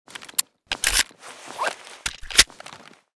usp_reload.ogg.bak